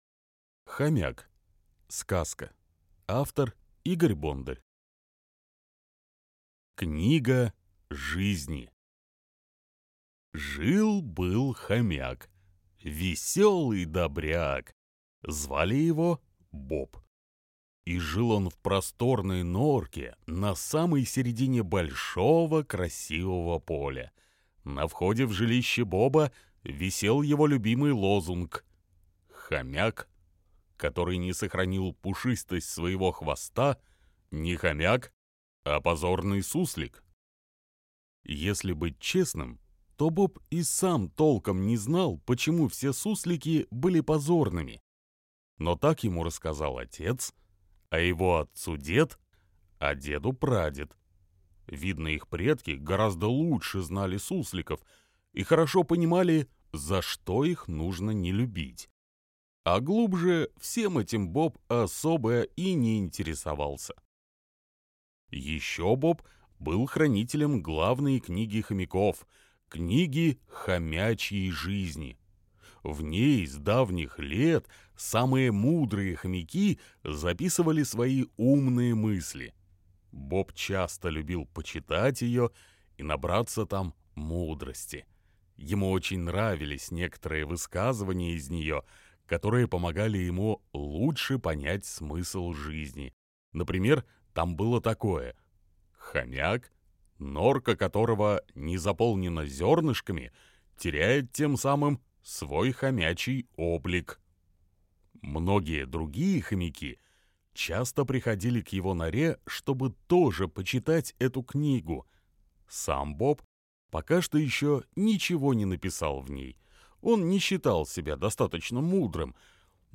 Хомяк - аудиосказка